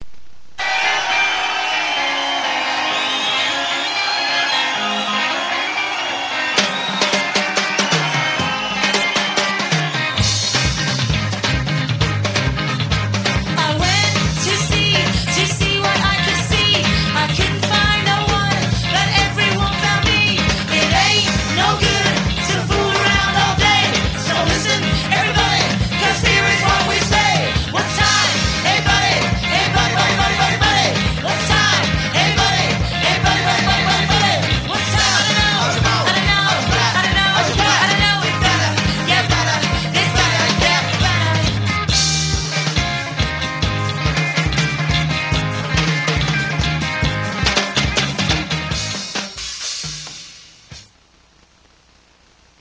214Kb Live (Edit)